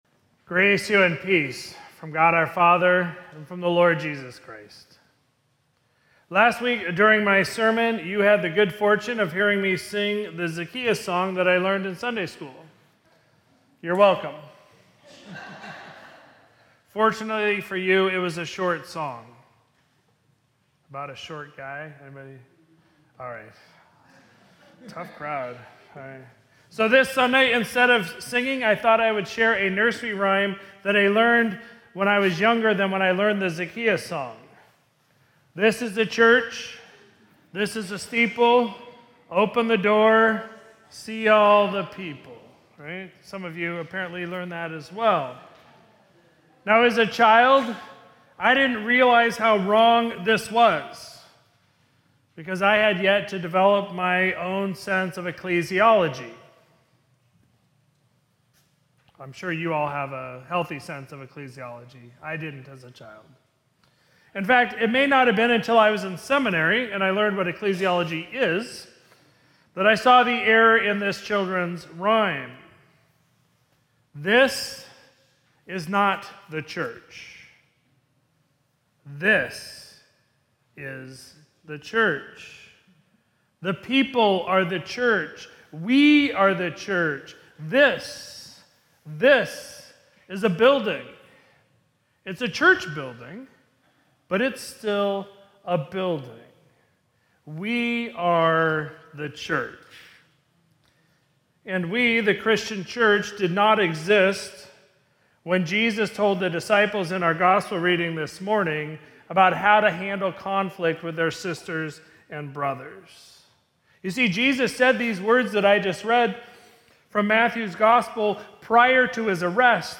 Sermon from Sunday, March 15, 2026